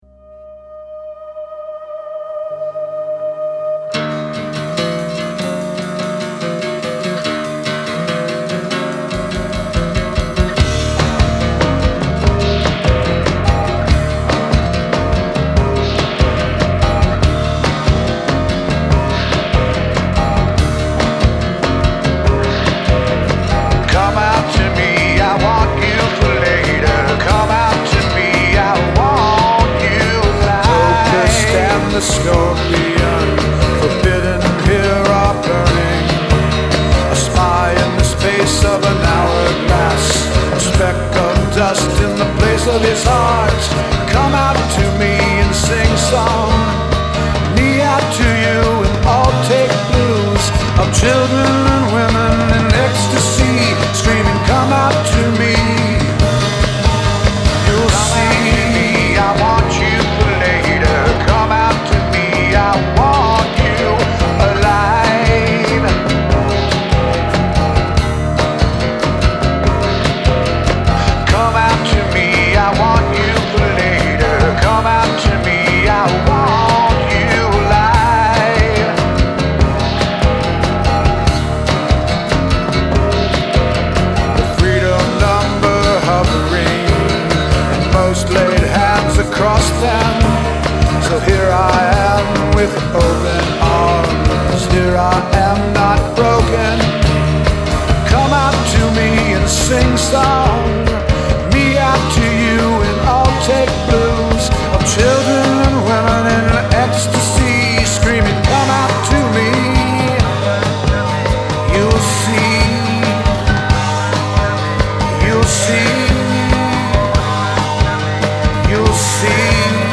moods ranging from jubilant to melancholy to mean